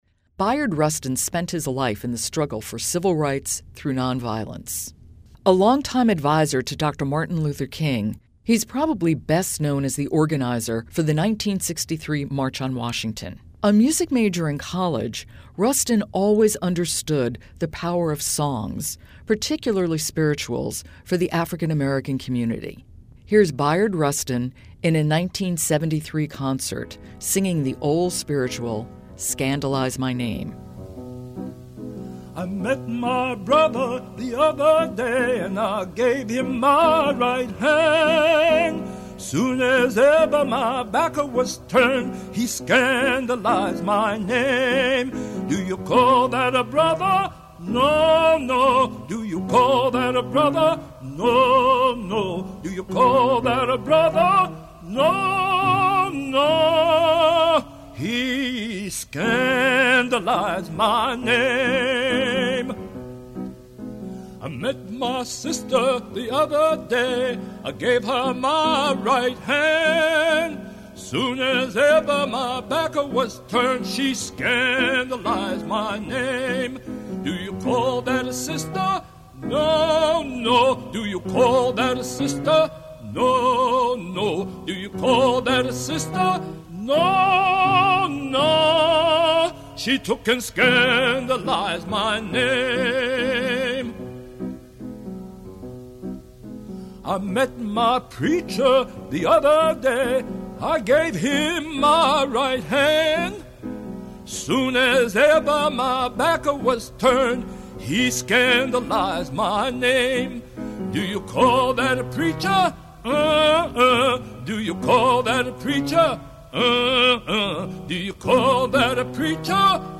Here's a rare recording of the great civil rights leader Bayard Rustin singing an old spiritual in a live concert.
Here is Bayard Rustin, in a 1973 concert, singing the old spiritual, Scandalize My Name.